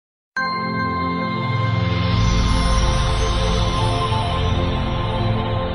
Tarot_Pickcardsounds.mp3